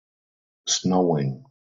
/ˈsnəʊɪŋ/